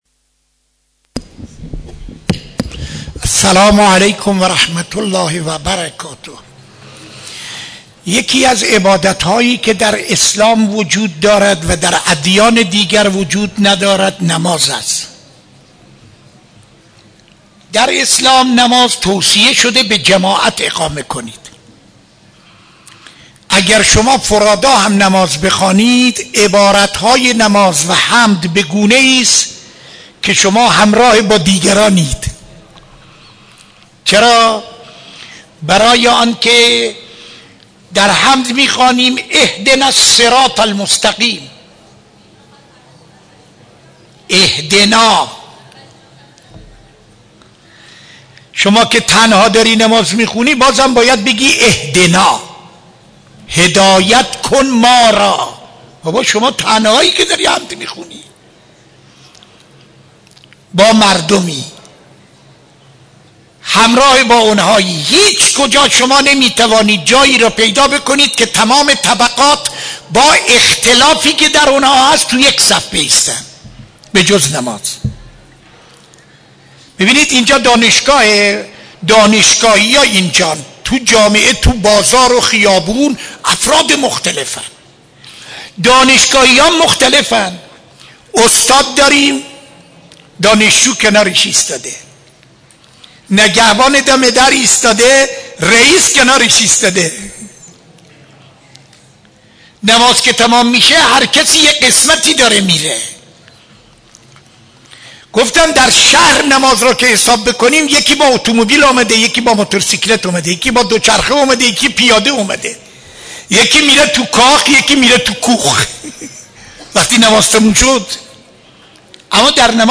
سخنرانی در مسجد دانشگاه (یکشنبه 25-11-94)